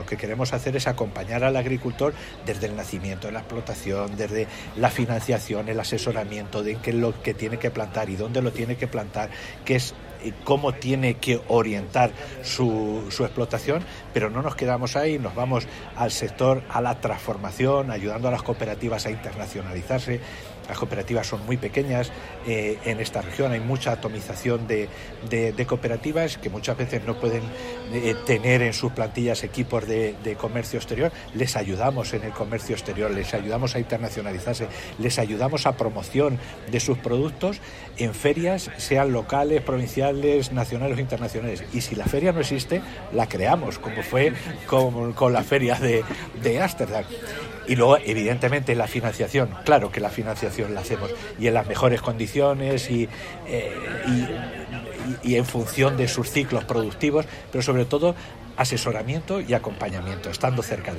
Globalcaja, aliado de valor del sector agroalimentario, abre su espacio en la 61º Feria Nacional del Campo de Manzanares